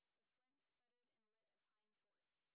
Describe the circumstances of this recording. sp29_white_snr20.wav